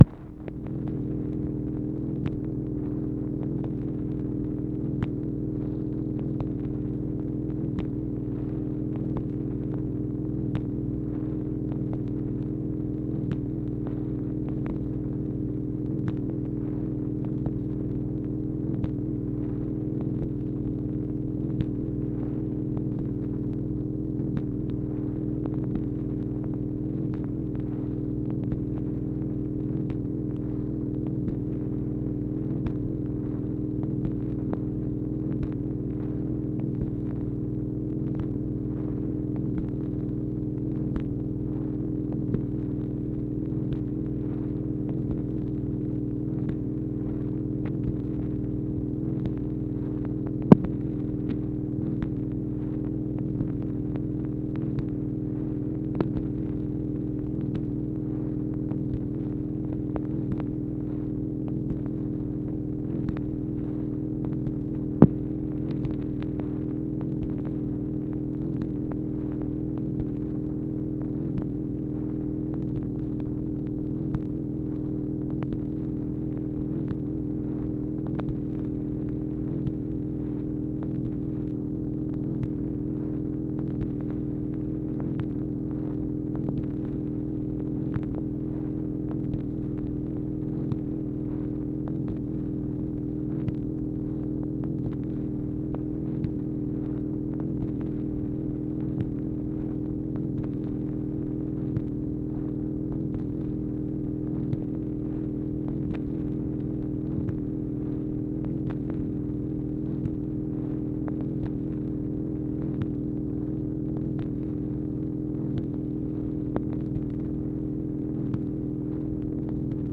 MACHINE NOISE, January 10, 1964
Secret White House Tapes | Lyndon B. Johnson Presidency